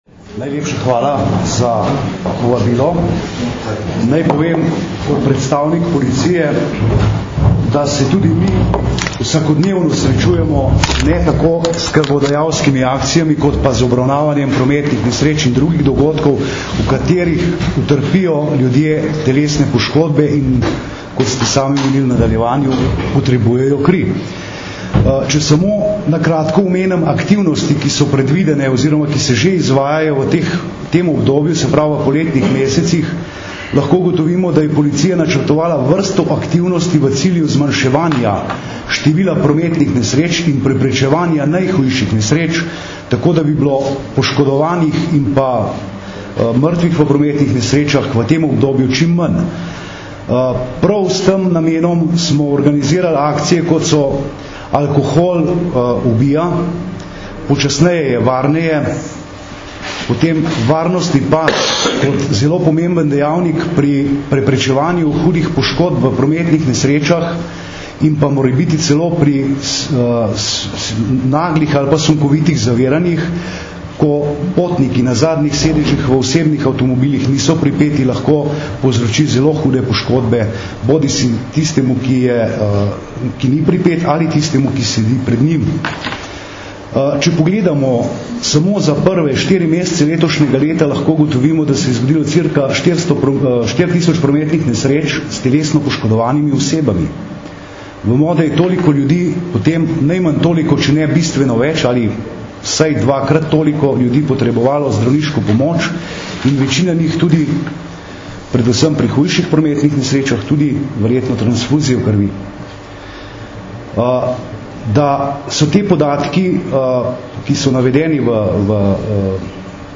Ob 4. juniju, nacionalnem dnevu krvodajalstva, je včeraj, 3. junija 2009, Rdeči križ Slovenije organiziral novinarsko konferenco, na kateri je sodeloval tudi predstavnik policije.
Zvočni posnetek izjave